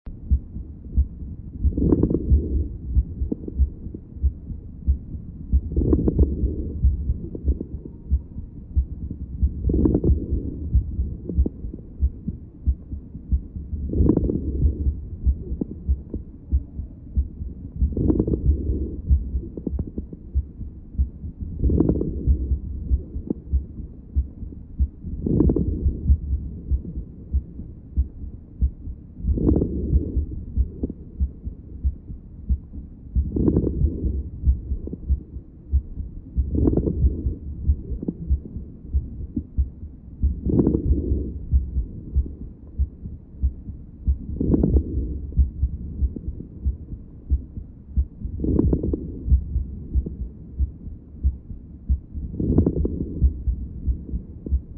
XinFeiTingZhen_Dog - 心肺听诊
中湿罗音.mp3